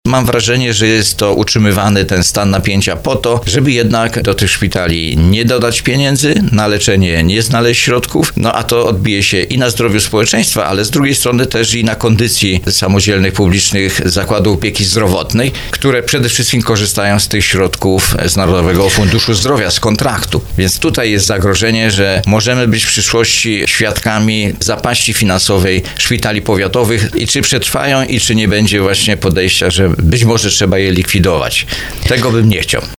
Trudna sytuacja finansowa Narodowego Funduszu Zdrowia może doprowadzić do poważnego kryzysu w polskim systemie szpitalnictwa – ostrzegł w audycji Słowo za Słowo radny Sejmiku Województwa Małopolskiego, Józef Gawron.